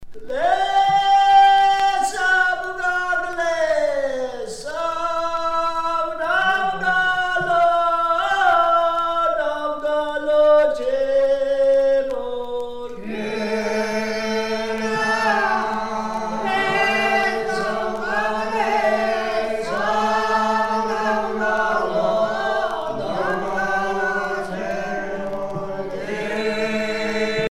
Chants de travail
Pièce musicale éditée